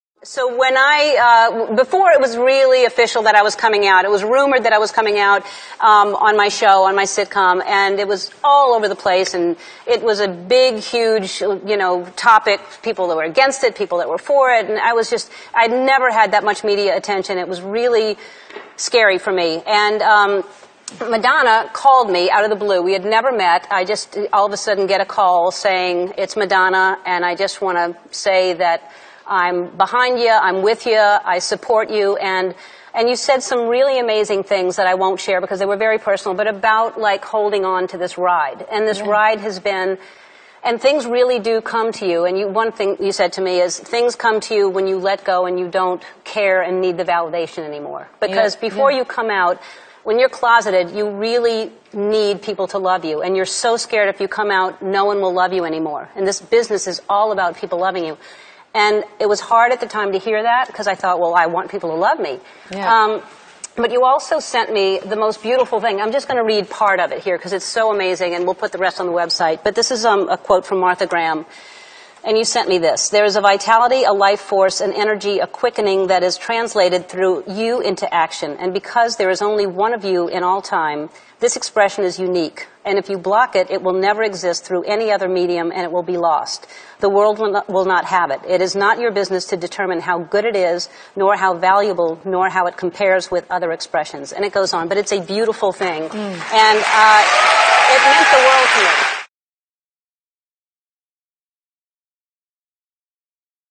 在线英语听力室艾伦脱口秀16 Modonna's moving word的听力文件下载,艾伦脱口秀是美国CBS电视台的一档热门脱口秀，而主持人Ellen DeGeneres以其轻松诙谐的主持风格备受青睐。